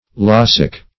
Search Result for " laccic" : The Collaborative International Dictionary of English v.0.48: Laccic \Lac"cic\ (l[a^]k"s[i^]k), a. [Cf. F. laccique.]